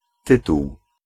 Ääntäminen
Synonyymit libellé aloi intitulé Ääntäminen France: IPA: [titʁ] Haettu sana löytyi näillä lähdekielillä: ranska Käännös Ääninäyte Substantiivit 1. tytuł {m} Suku: m .